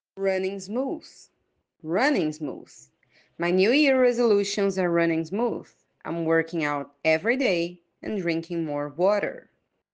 ATTENTION TO PRONUNCIATION 🗣